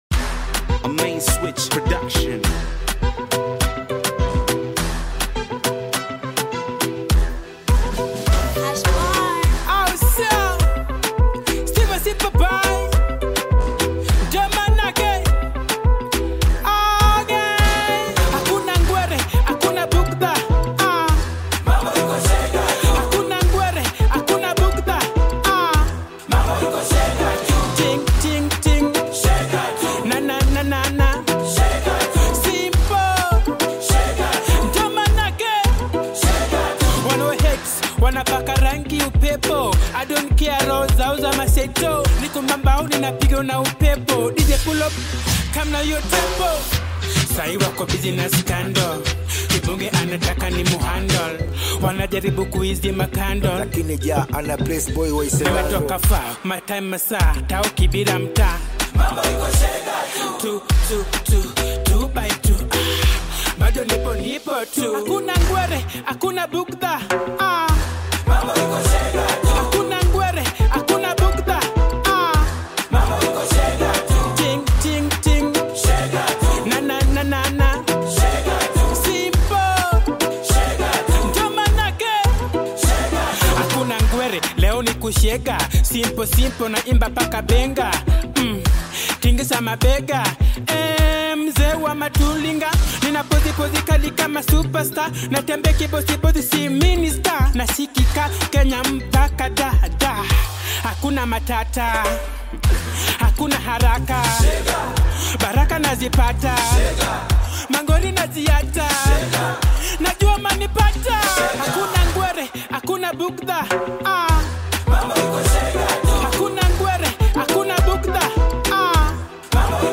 Through its upbeat tempo and infectious rhythm